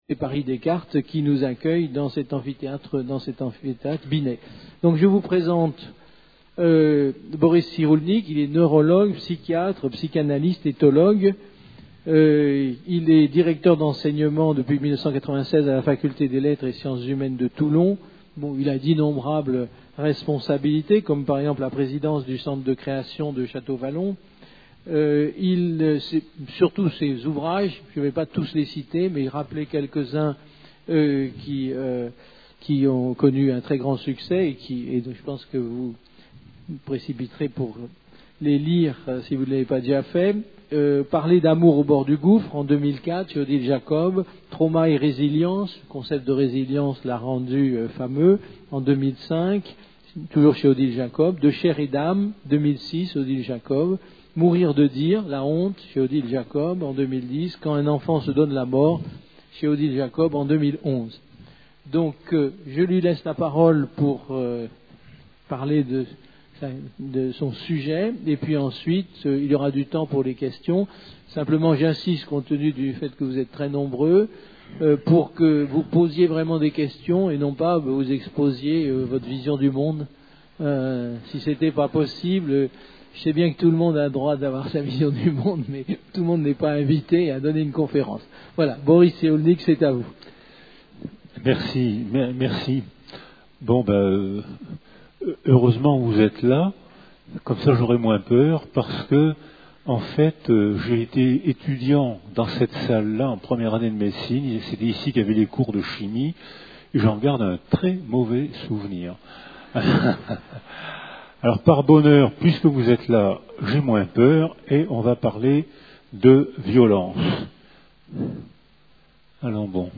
Une conférence du cycle "La violence aujourd'hui" Violences des représentations par Boris Cyrulnik, neurologue, psychiatre